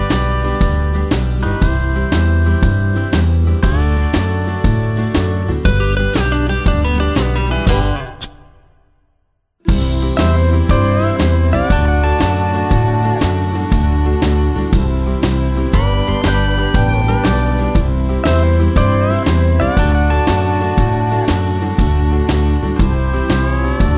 no Backing Vocals Country (Male) 2:59 Buy £1.50